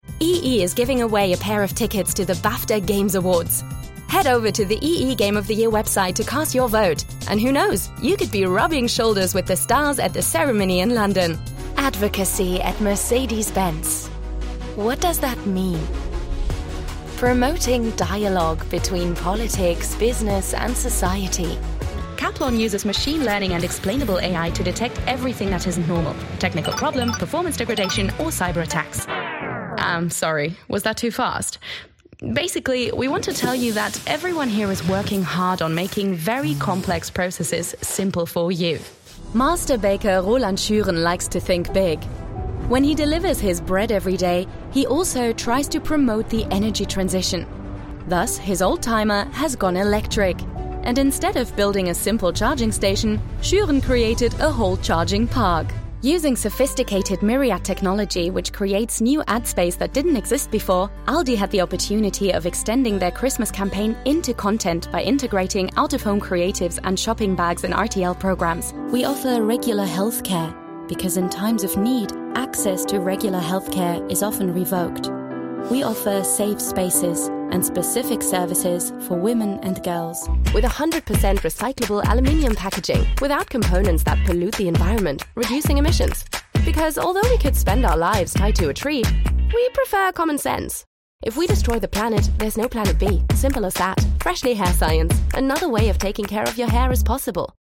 Vídeos corporativos
Mi voz es identificable, contemporánea y juvenil con un sonido cálido y texturizado.
Desde mi estudio hogareño con calidad de transmisión en Londres, he trabajado con cientos de clientes de todo el mundo, entregando un trabajo de primera calidad en alemán, inglés y francés.